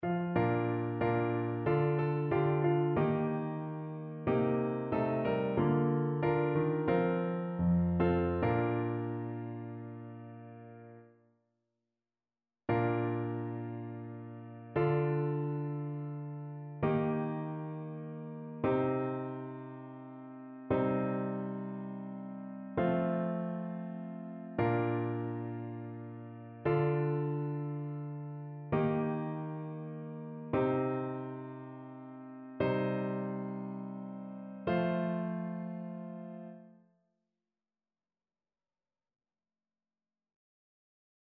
ChœurSopranoAltoTénorBasse
annee-abc-temps-du-careme-jeudi-saint-psaume-115-satb.mp3